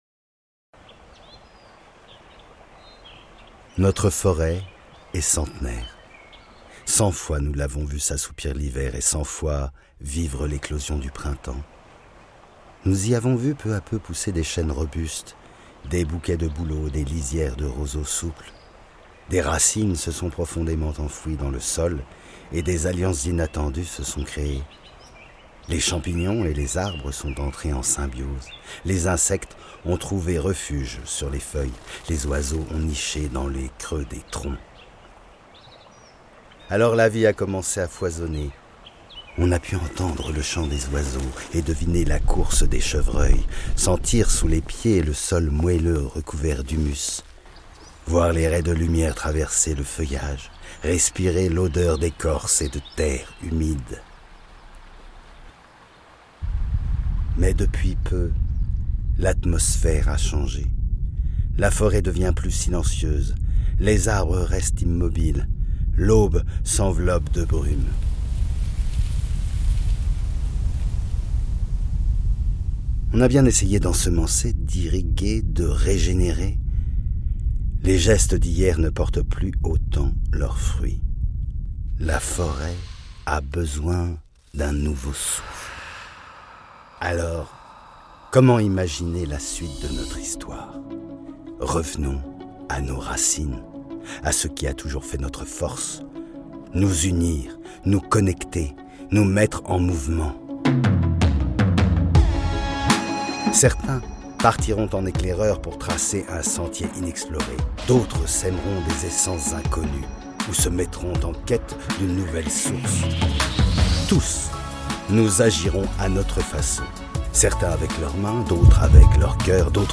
Voix off
42 - 67 ans - Baryton